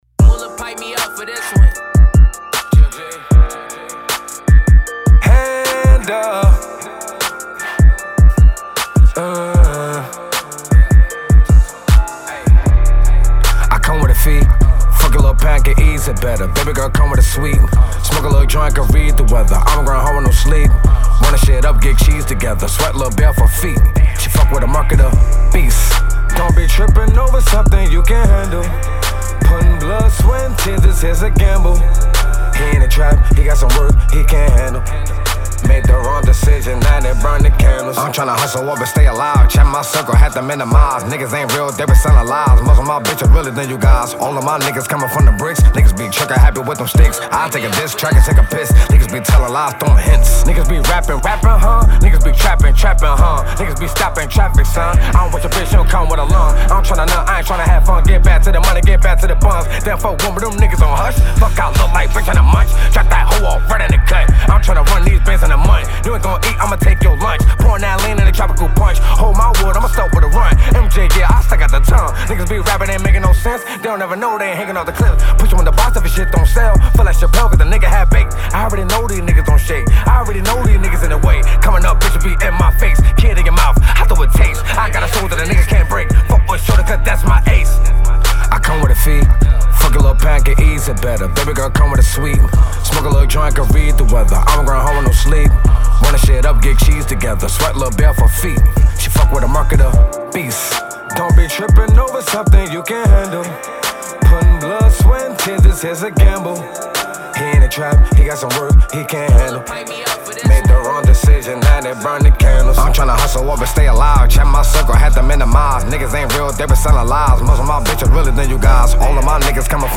Rap Artist